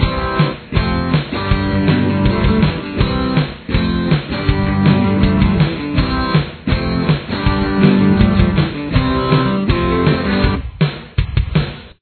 Chorus
There are 2 different guitar parts in the chorus.